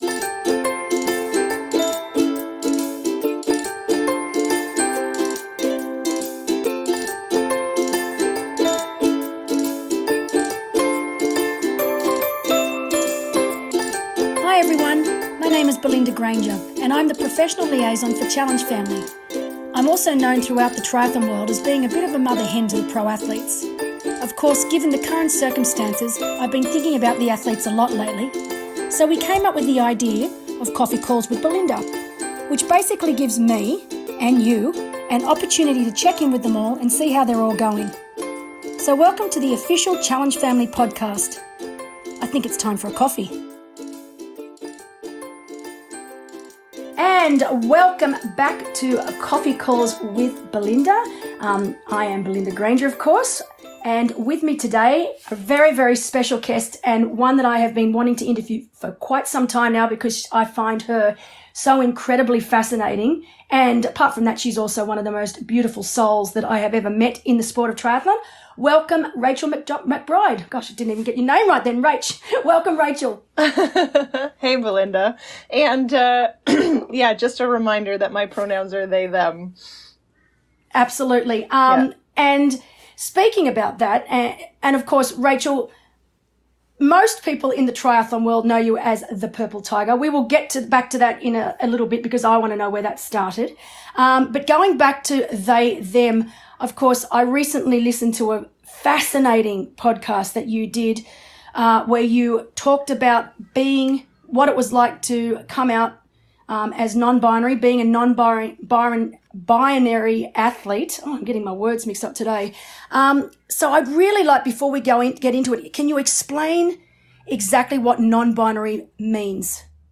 We had a really great conversation